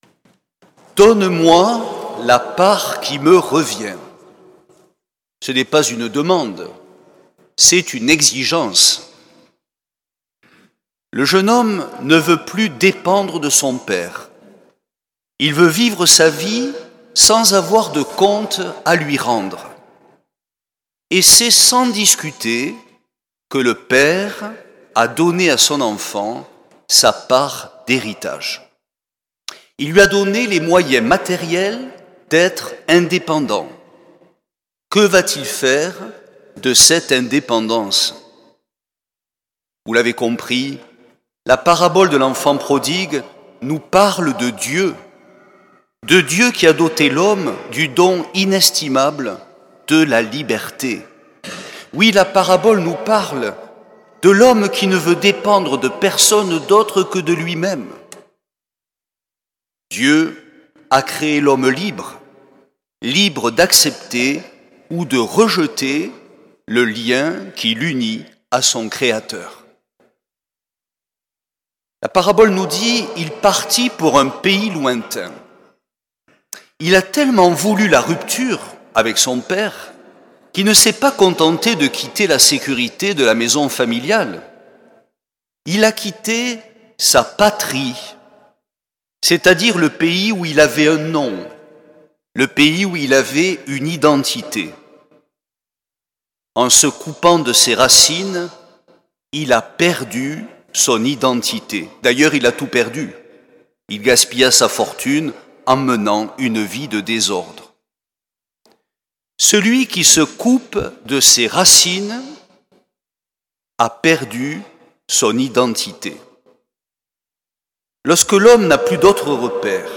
L’enregistrement retransmet l’homélie